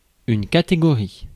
Ääntäminen
Synonyymit classe Ääntäminen France: IPA: [ka.te.ɡɔ.ʁi] Haettu sana löytyi näillä lähdekielillä: ranska Käännös Konteksti Ääninäyte Substantiivit 1. category matematiikka US 2. bracket US Suku: f .